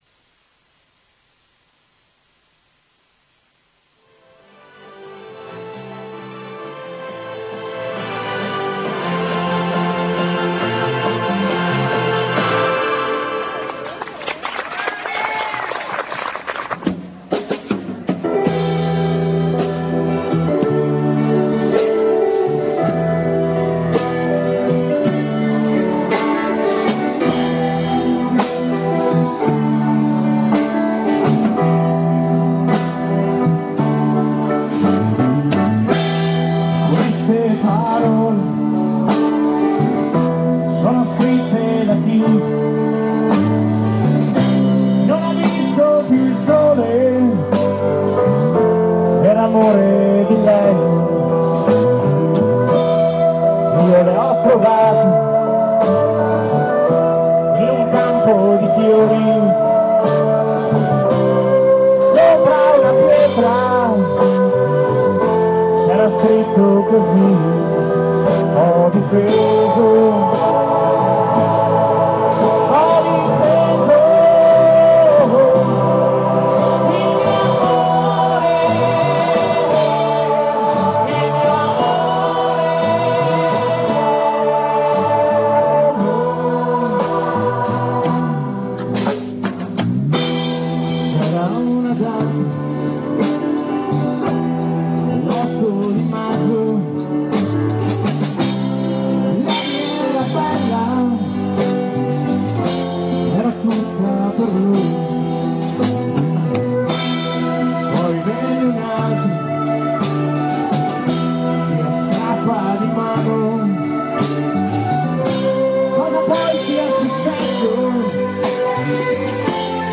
Cover Band
Colle Umberto (TV), 1998